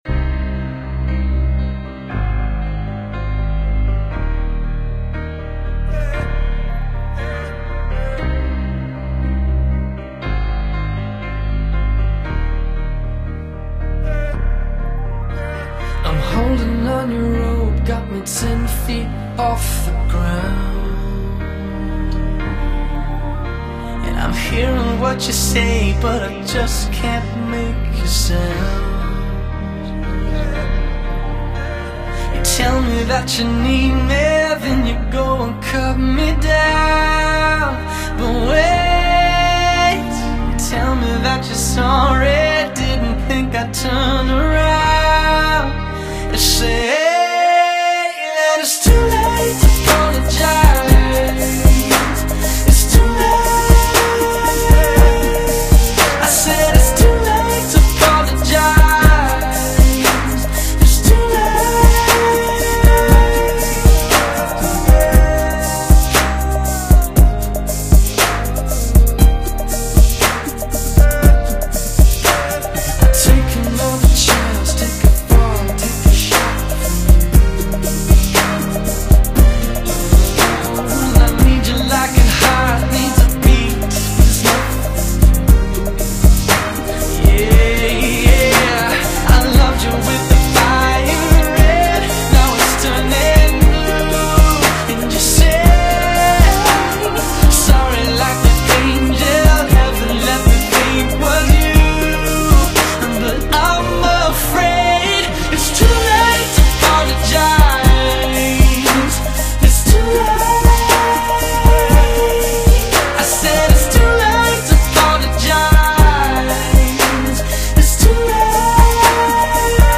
Жанр:Hip-Hop,Rap,R&B,Pop,Rock...